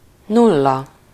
Ääntäminen
France: IPA: [ʃifʁ]